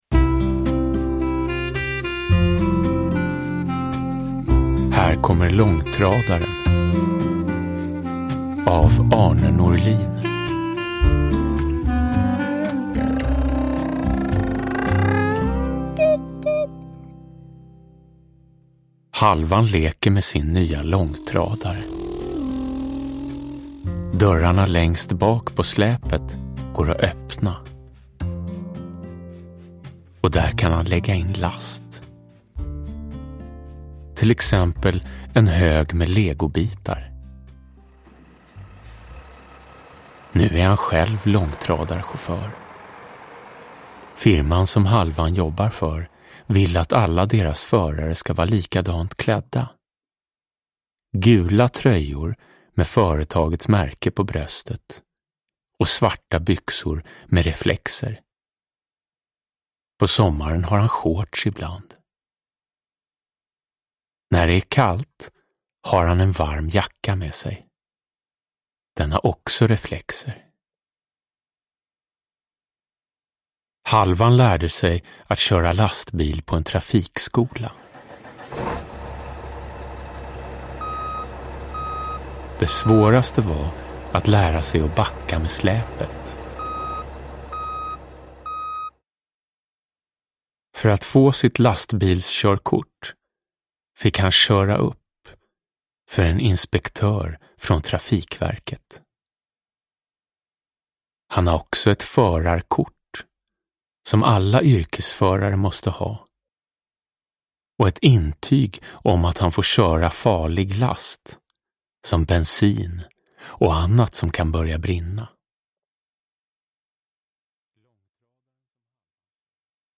Här kommer långtradaren – Ljudbok – Laddas ner
Uppläsare: Jonas Karlsson